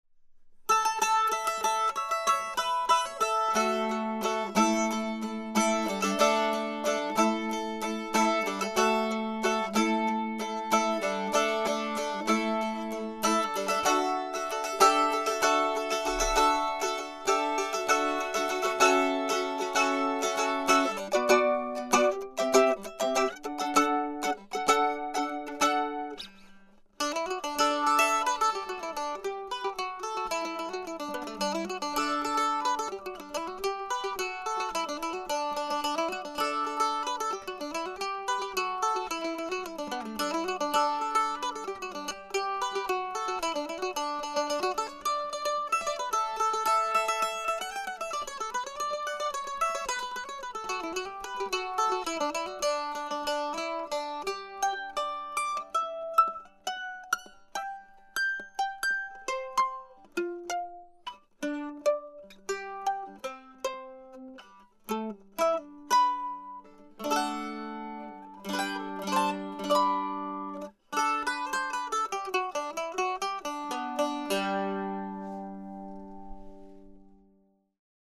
Mandoline
Mando-Framus.mp3